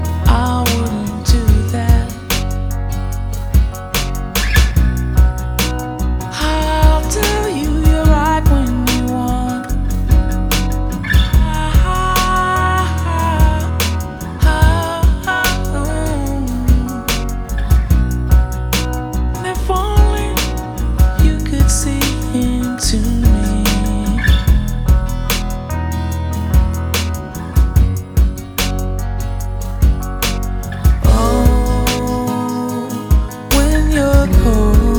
Жанр: Поп / R&b / Рок / Соул